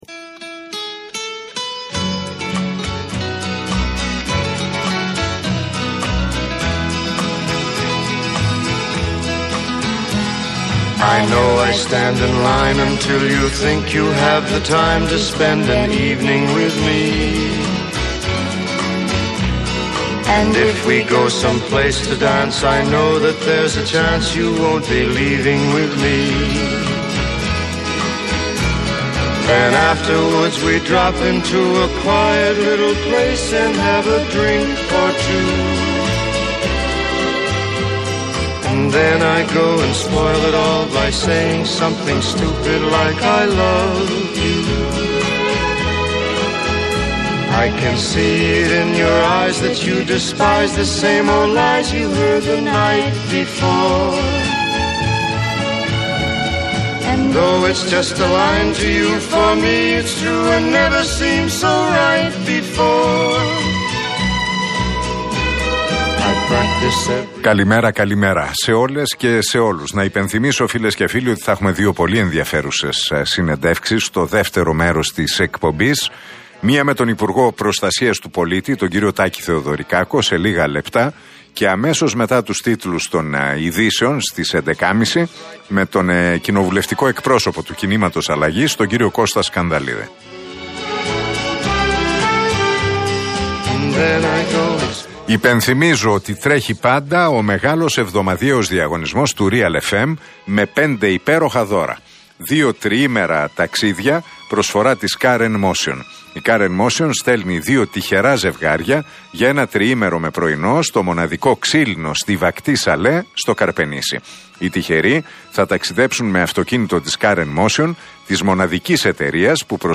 Ακούστε την εκπομπή του Νίκου Χατζηνικολάου στον Real Fm 97,8, την Τετάρτη 19 Ιανουαρίου 2022.